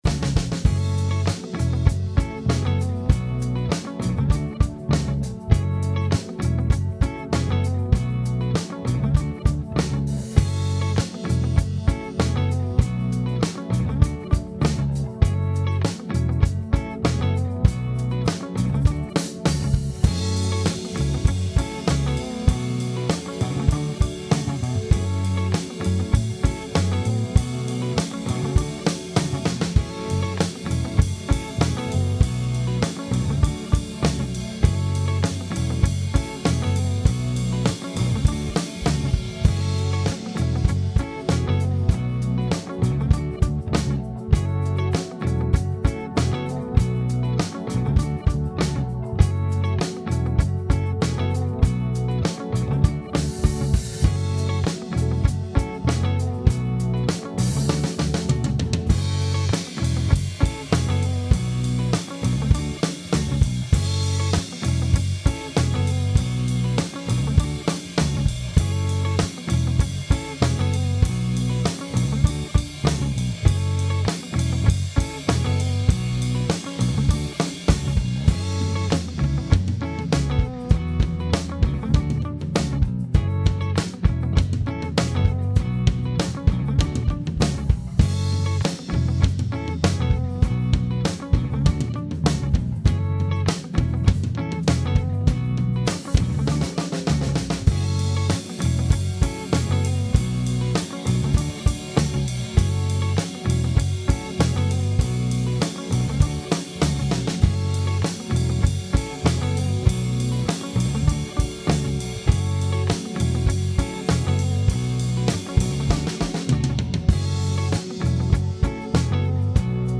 looping layers of rhythm and lead guitar tracks
Additional bass and keyboard pad brought a little more atmosphere to the piece.